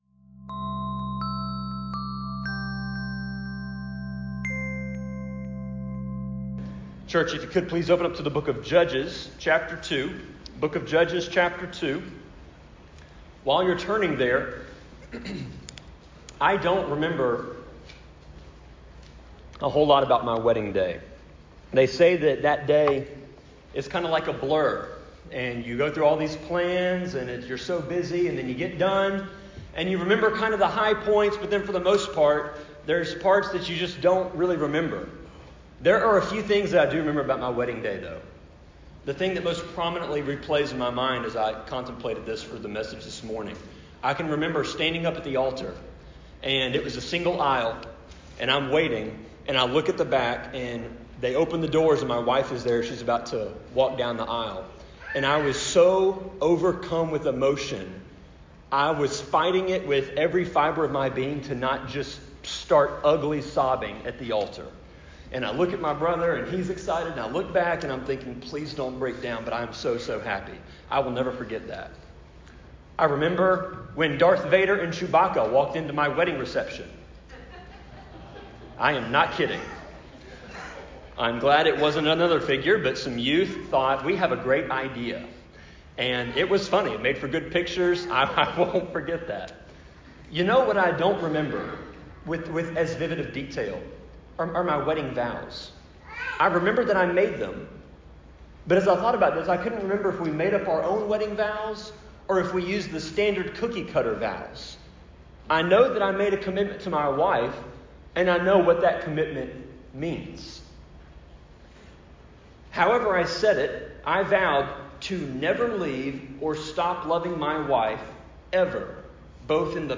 Sermon-25.9.14-CD.mp3